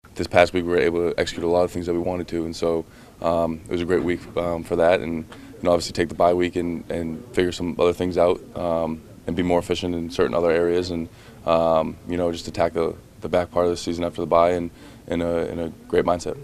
Tight end Pat Freiermuth says there is more work for the Steelers to do.